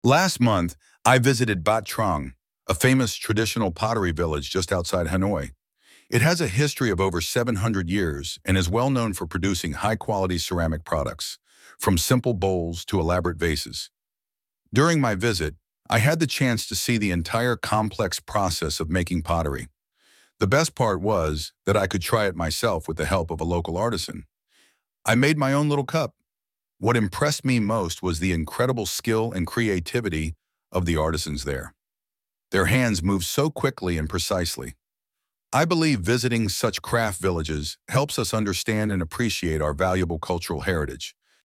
II. You will hear a student giving a presentation about a traditional craft village.